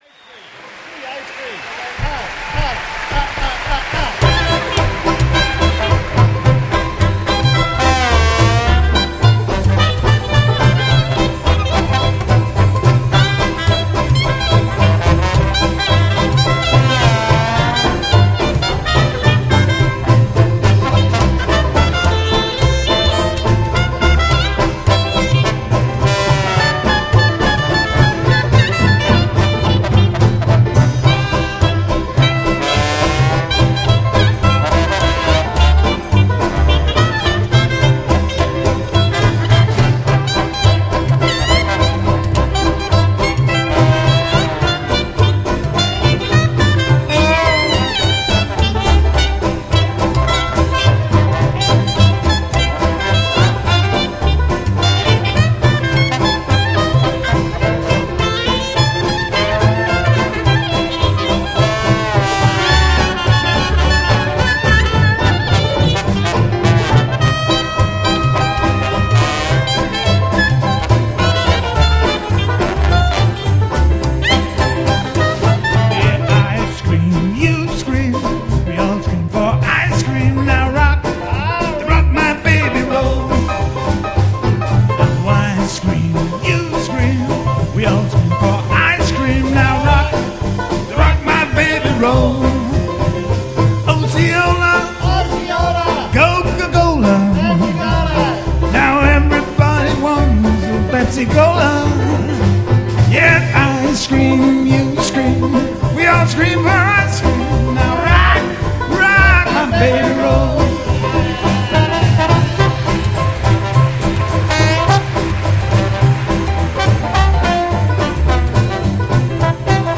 وودي آلان يعزف على الكلارينت ؟